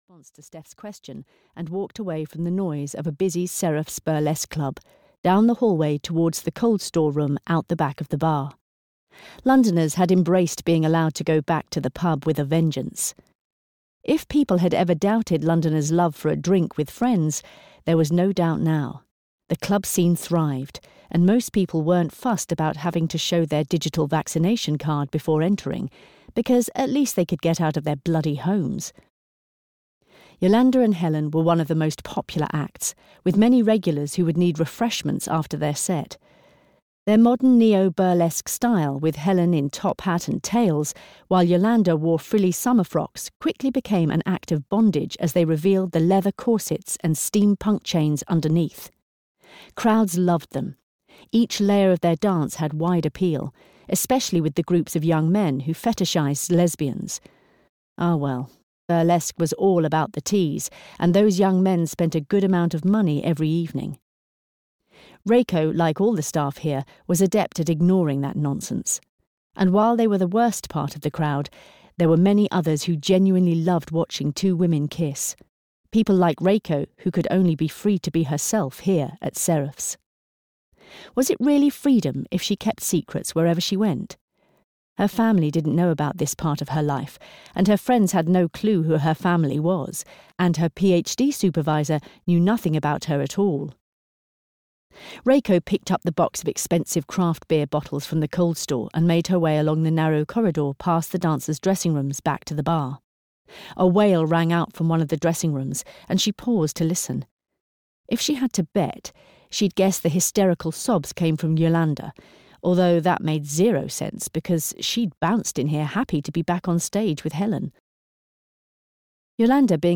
Show Up (EN) audiokniha
Ukázka z knihy